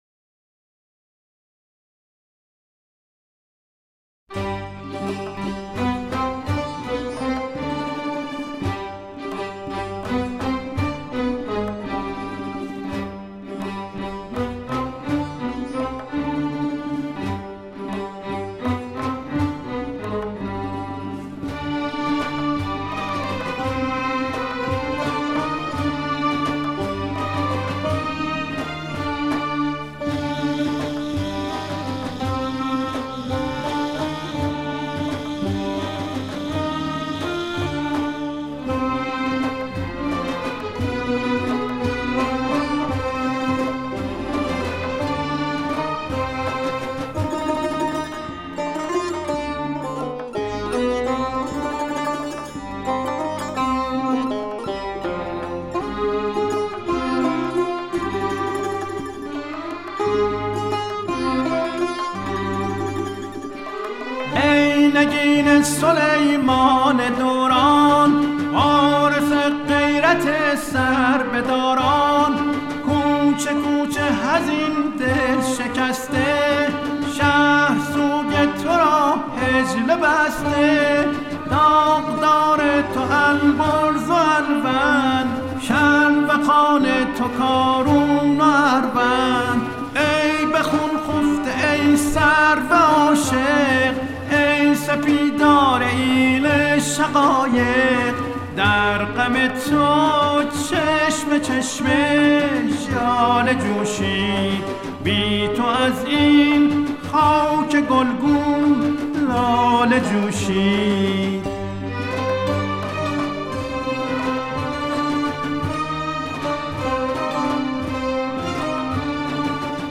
از خوانندگان موسیقی سنتی و پاپ ایرانی است